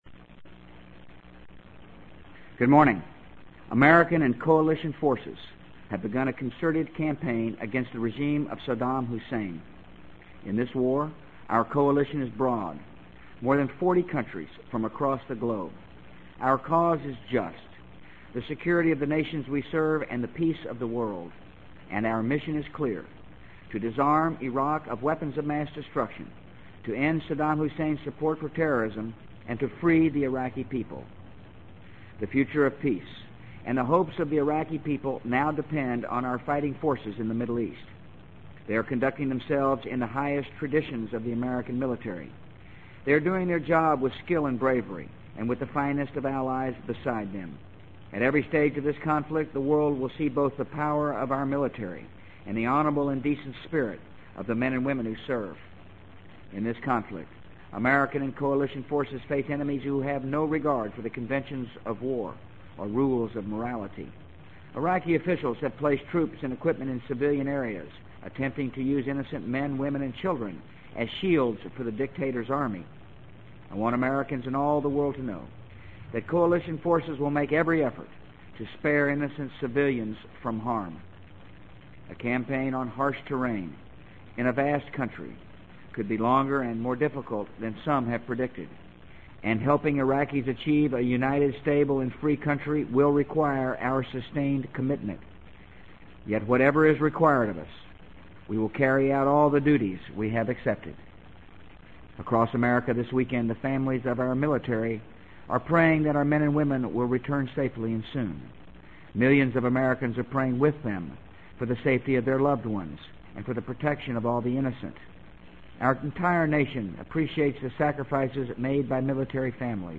【美国总统George W. Bush电台演讲】2003-03-22 听力文件下载—在线英语听力室